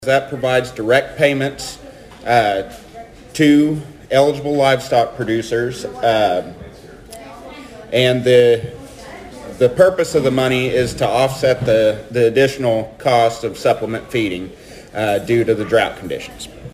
The Thayer and Mammoth Spring Rotary met Wednesday for their weekly meeting.